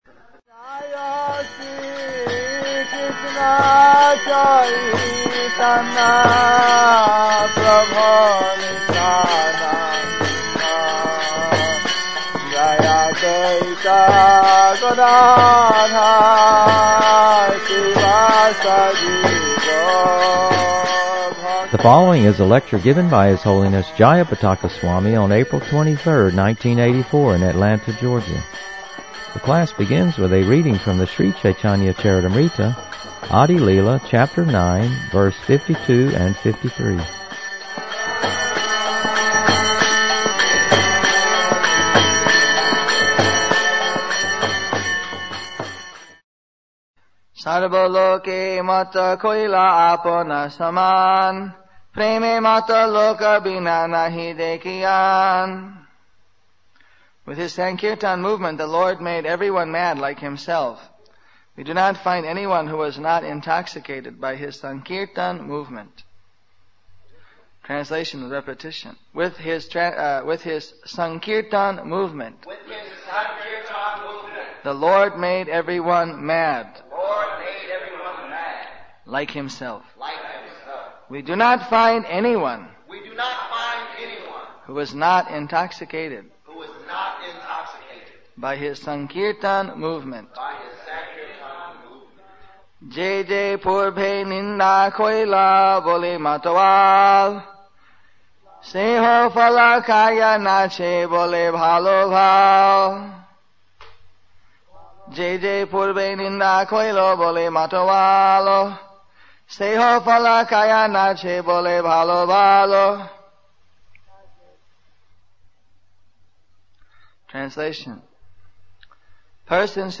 The class begins with a reading from the Sri Caitanya-Caritamrta, Adi-lila, Chapter 9, Verse 52 and 53.